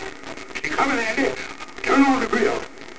While the focus of this page is not the Amos 'n' Andy show, I cannot resist tossing in an image and a wave file from one of my favorite episodes--The Diner.